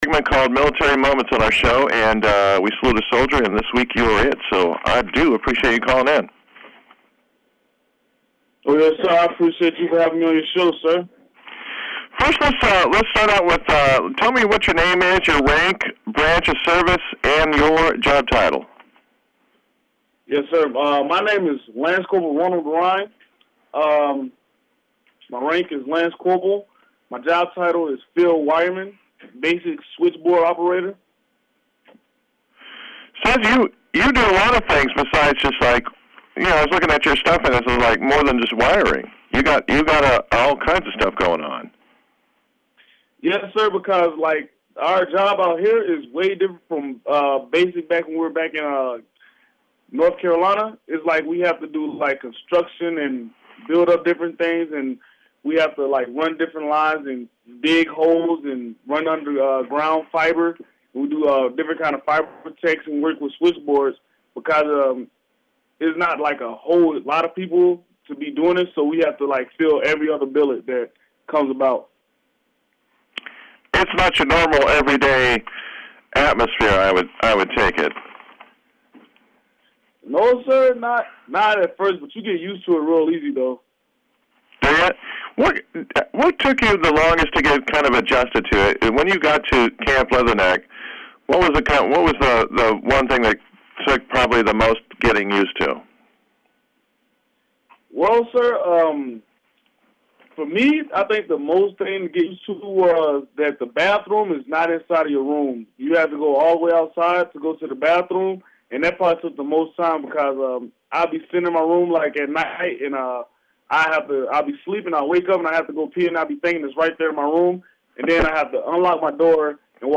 talks to a Radio Lia reporter